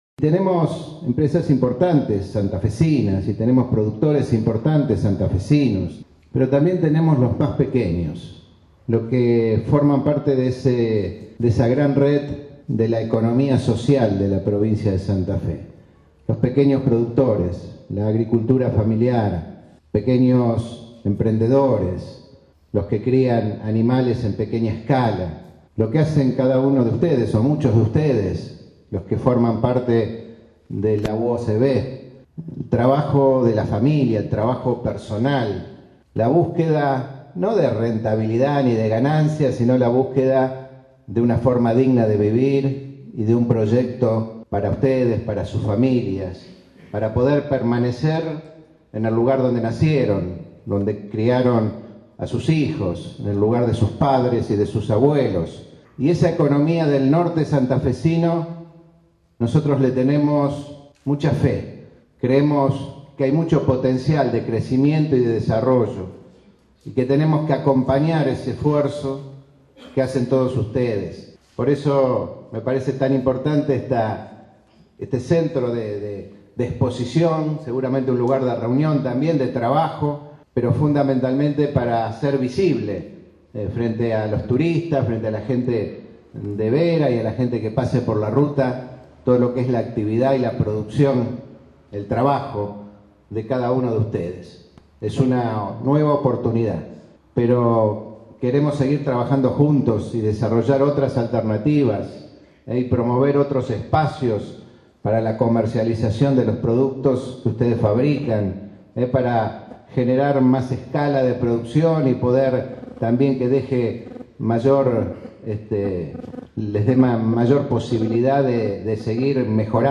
Lifschitz participó de la inauguración de la sede social de la organización de pequeños productores de la Cuña Boscosa y los Bajos Submeridionales